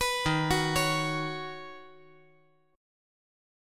Ebm7#5 Chord
Listen to Ebm7#5 strummed